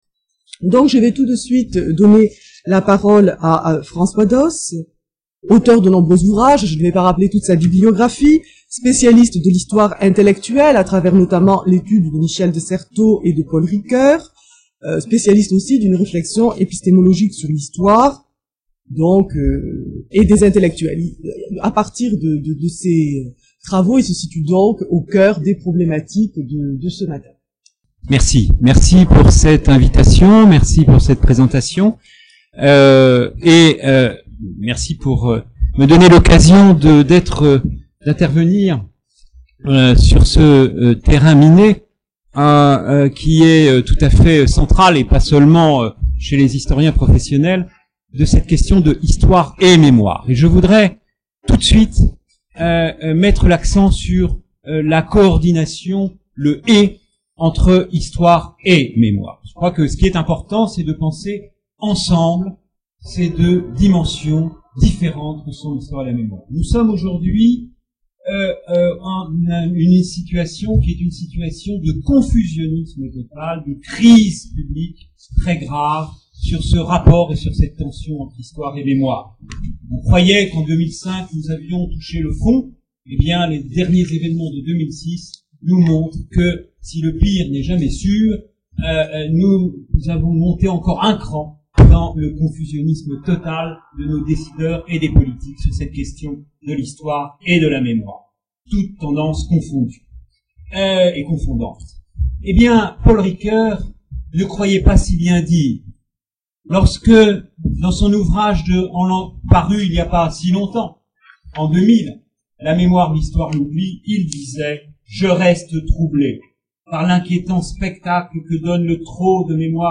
Conférence de François Dosse IUFM de Créteil.